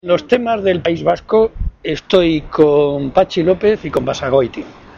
El secretario general de los socialistas castellano-manchegos y presidente del Consejo Territorial del PSOE, José María Barreda, ha señalado, a su llegada a la reunión de este órgano, que con el cambio de Gobierno ha habido una inflexión muy importante y perceptible por todos.